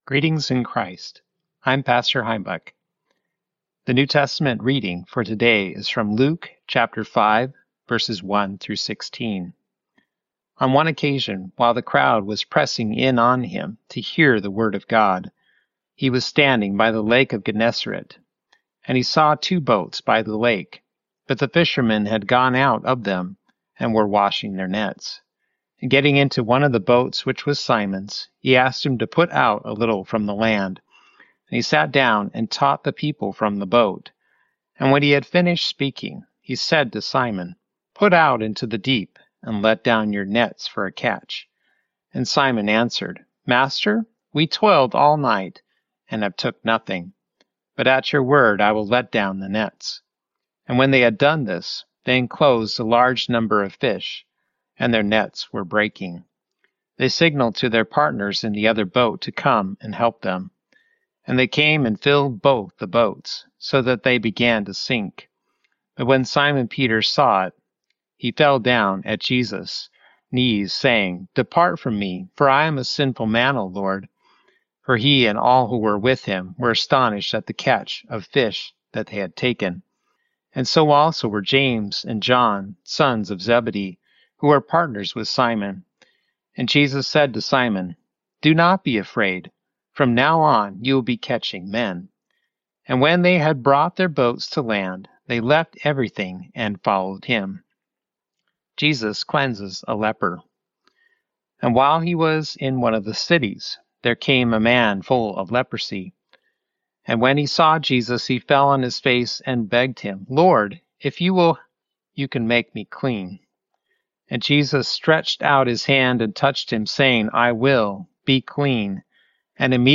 Morning Prayer Sermonette: Luke 5:1-16
Hear a guest pastor give a short sermonette based on the day’s Daily Lectionary New Testament text during Morning and Evening Prayer.